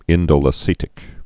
(ĭndō-lə-sētĭk)